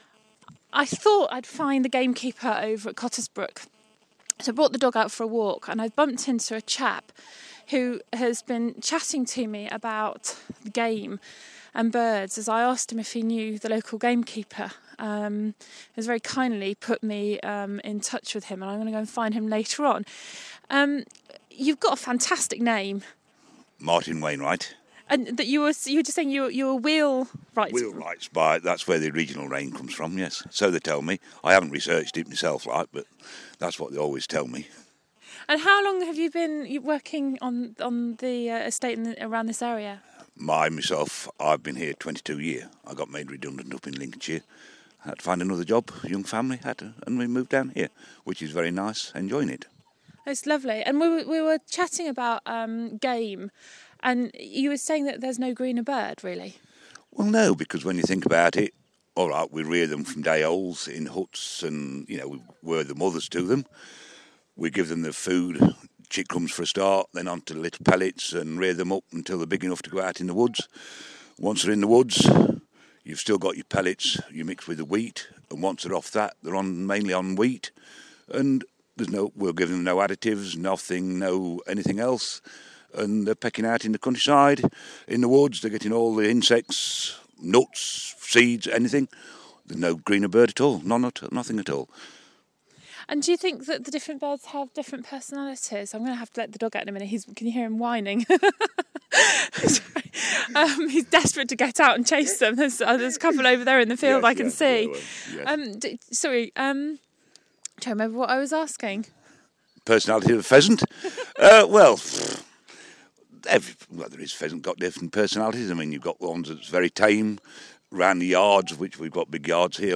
Chatting to a chap who was a game keeper for 14 years
Out walking and meet this chap as I was walking the dog! . what an accent and he used to be a game keeper !